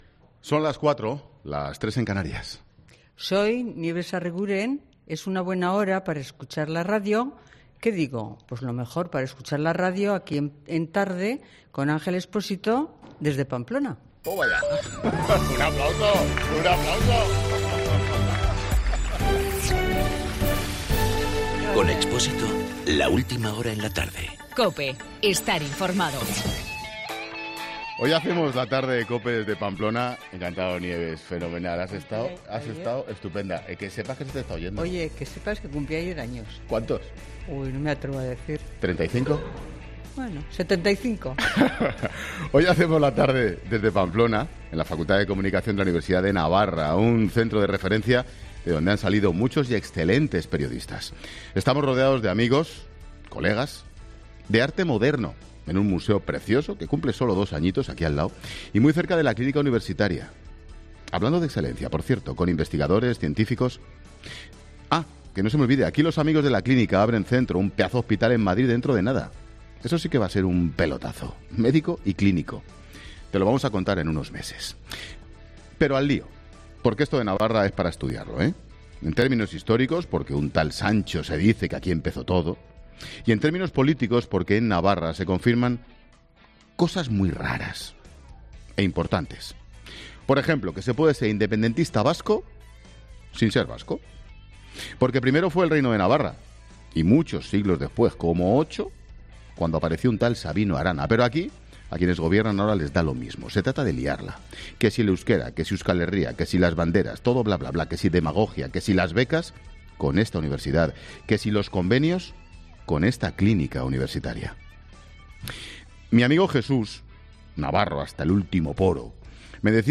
AUDIO: Monólogo 16 h.